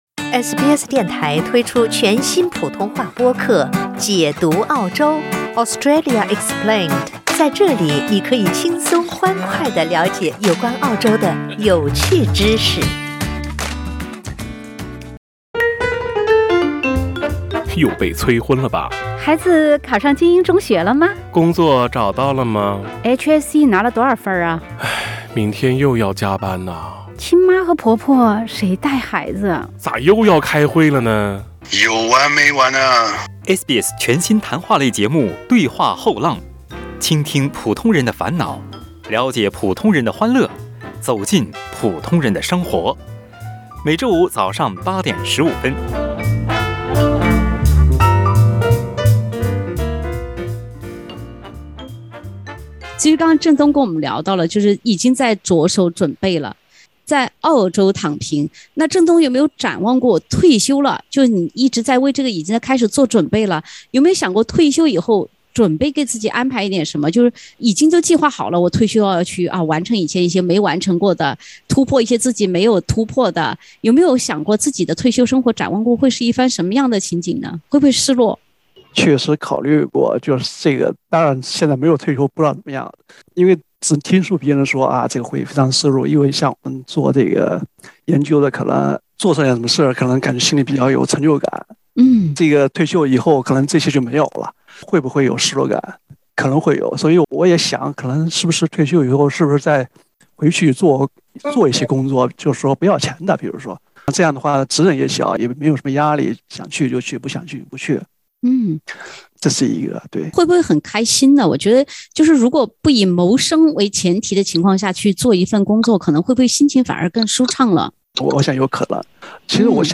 有躺平，就有躺不平。躺不平时该怎么办呢？（点击封面图片，收听有趣对话）